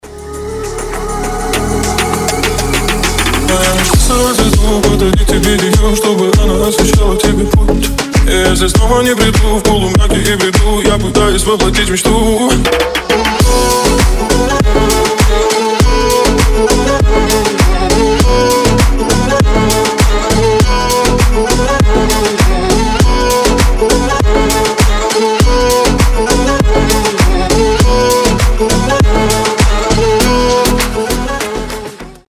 • Качество: 320, Stereo
deep house
восточные мотивы
Club House
красивая мелодия